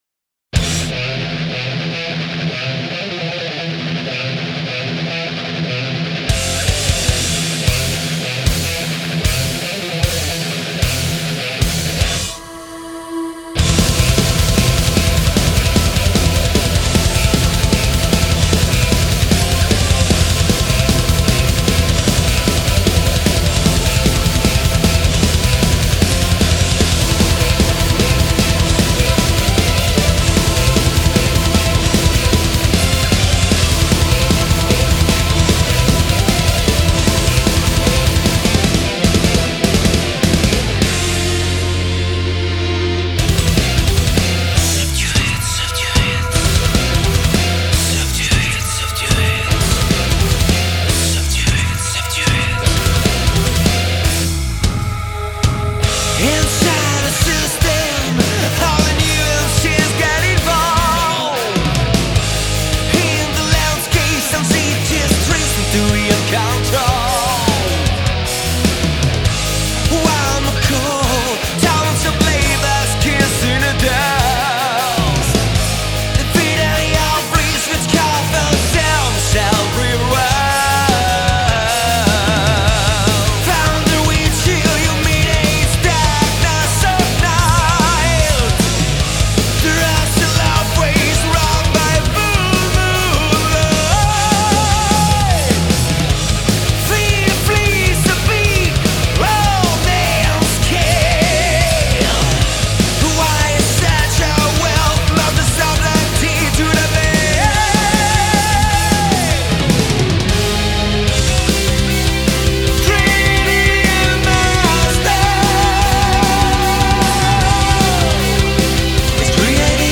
een combinatie van kracht, melodie, energie en techniek.
symfonische metalband